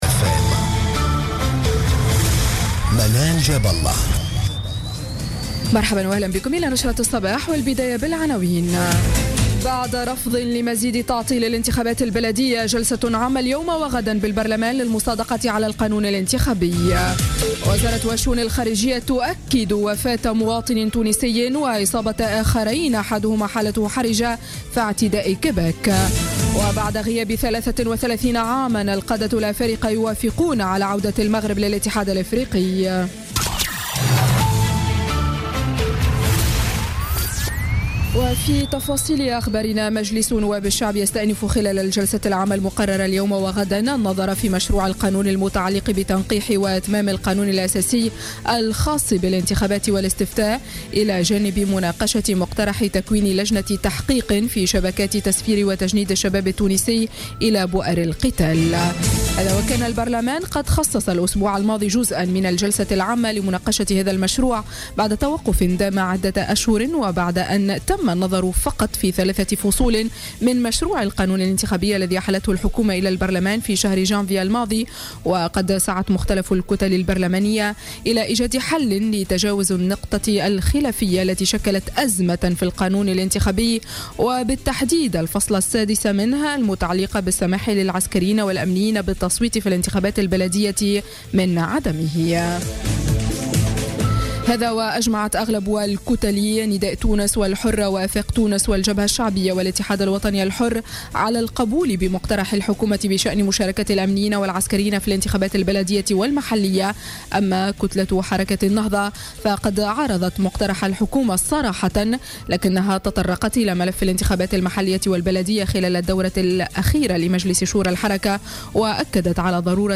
نشرة أخبار السابعة صباحا ليوم 31 جانفي 2017